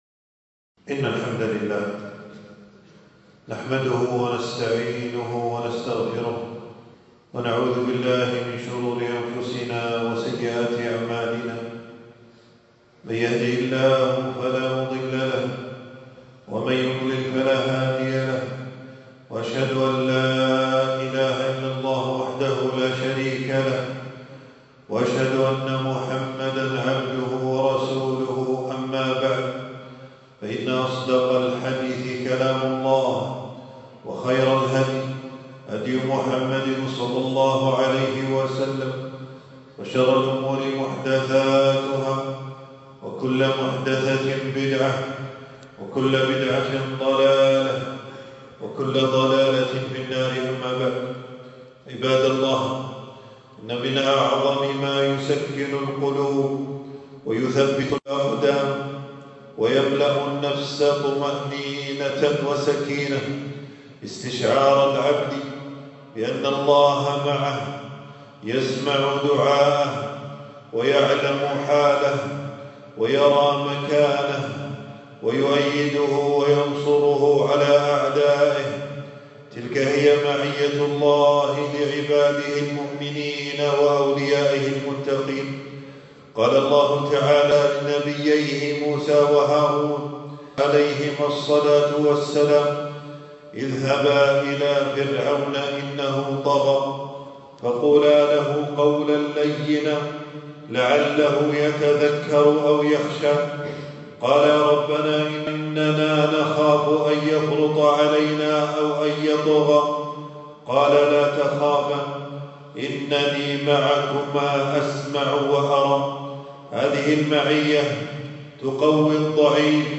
تنزيل تنزيل التفريغ خطبة بعنوان: مَعِيَّةُ اللهِ : أَسْبَابُهَا وَثَمَرَاتُهَا .
المكان: في مسجد - أبو سلمة بن عبدالرحمن 24رمضان 1447هـ (بمدينة المطلاع).